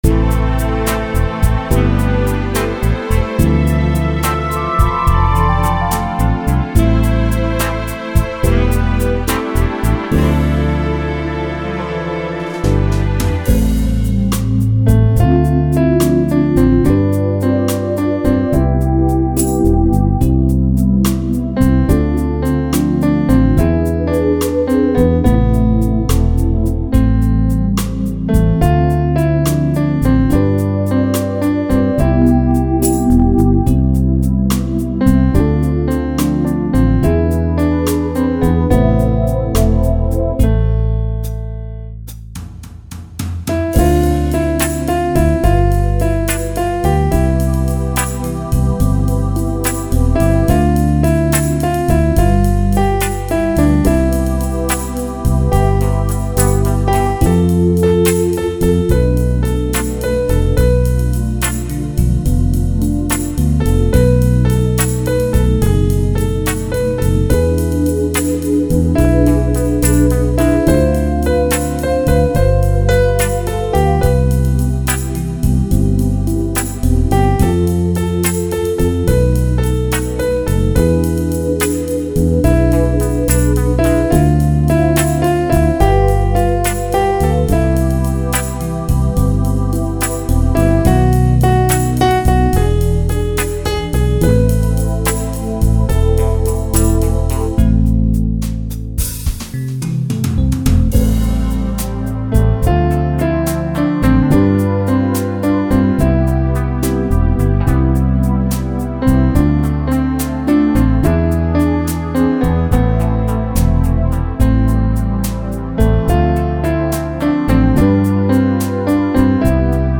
Така чуттєва зворушлива пісня... 16 Прекрасний дарунок мамі! 16 Божого благословення їй і довгих щасливих літ! friends flo05 flo05 flo05